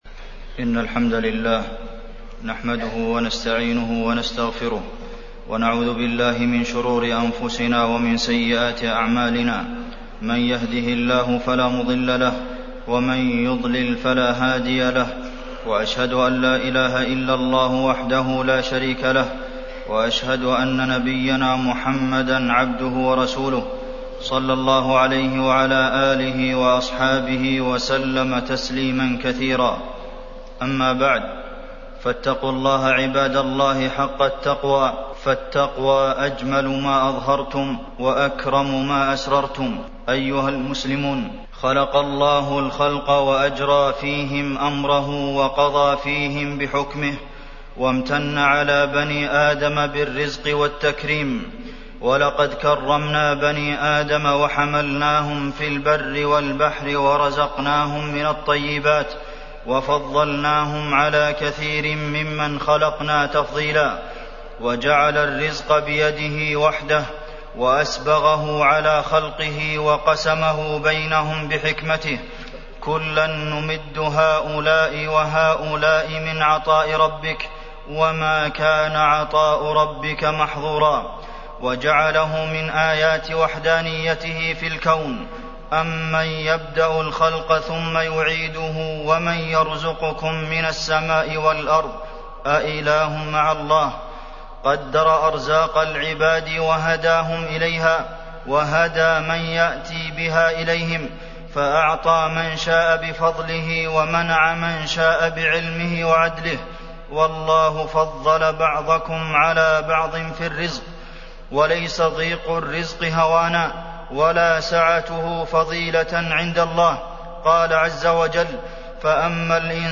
تاريخ النشر ١٧ ربيع الثاني ١٤٢٨ هـ المكان: المسجد النبوي الشيخ: فضيلة الشيخ د. عبدالمحسن بن محمد القاسم فضيلة الشيخ د. عبدالمحسن بن محمد القاسم الرزق